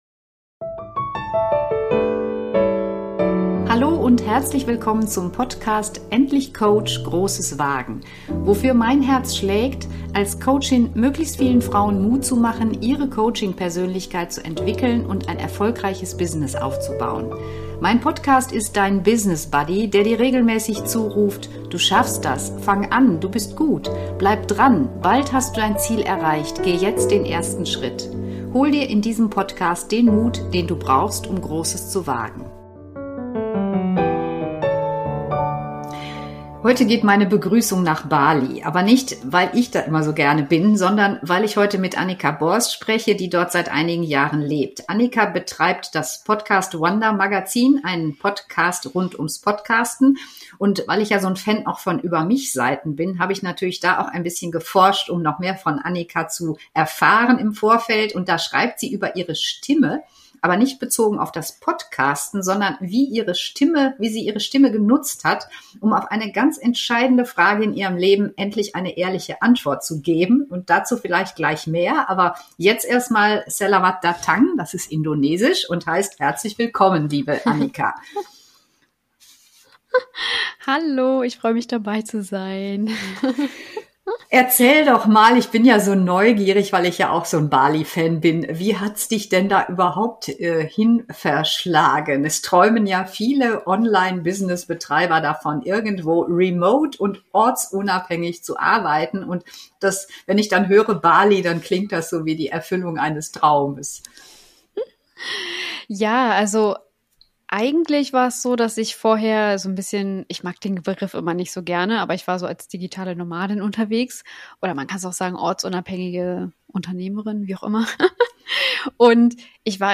#047 Interview